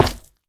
Minecraft Version Minecraft Version latest Latest Release | Latest Snapshot latest / assets / minecraft / sounds / block / froglight / break1.ogg Compare With Compare With Latest Release | Latest Snapshot
break1.ogg